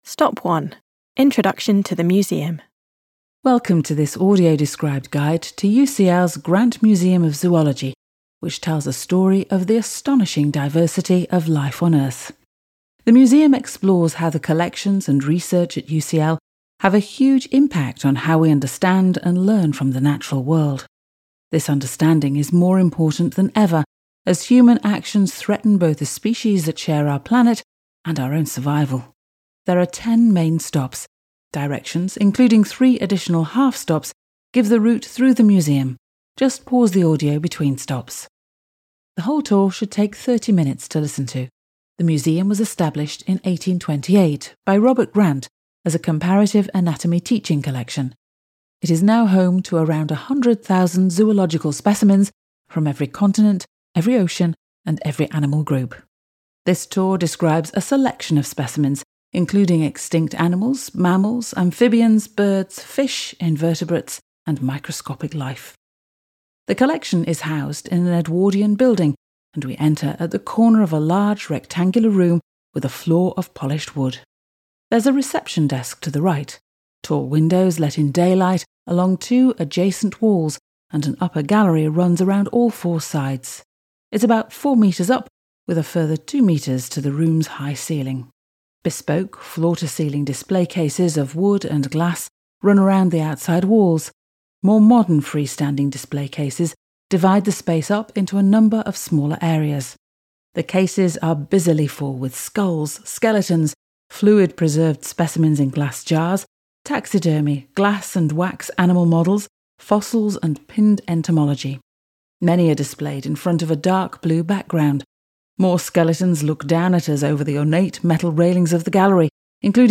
Audio described tour of the Grant Museum of Zoology